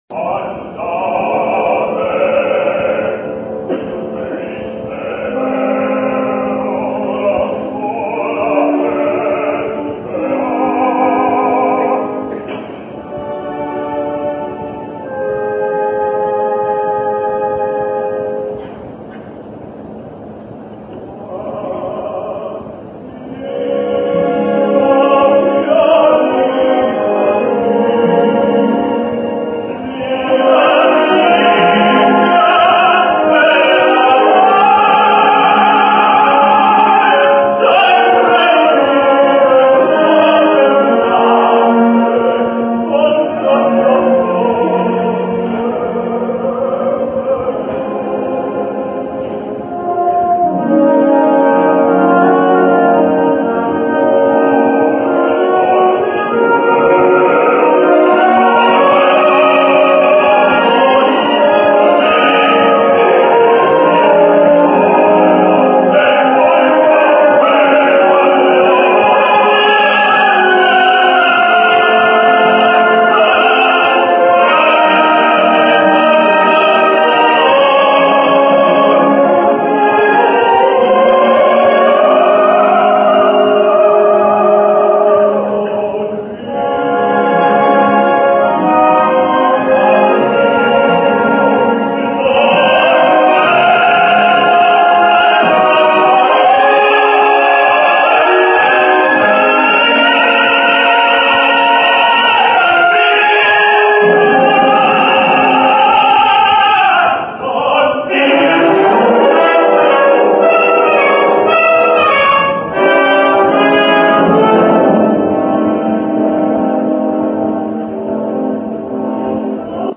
Addio, fiorito asil, with unknown baritone